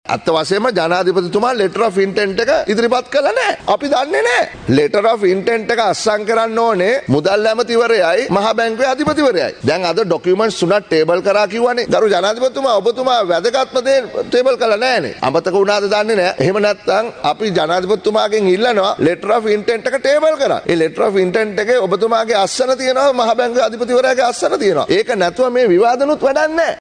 ජනාධිපතිවරයා මේ පිළිබද සිදු කළ කතාවේදී වැදගත්ම ලිපියක් සභාගත නොකල බවත්, අදාළ ලිපිය නොමැතිව විවාදය සිදු කර පළක් නොවන බවත් ඊයේ විවාදයේදී අදහස් පළ කරමින් සමගි ජන බලවේගයේ පාර්ලිමේන්තු මන්ත්‍රී ආචාර්ය හර්ෂ ද සිල්වා මහතා පැවසුවා.